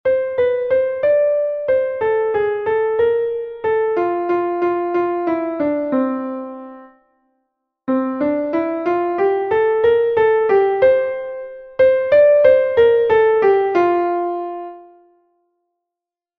Andantino.mp3